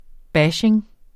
Udtale [ ˈbaɕeŋ ]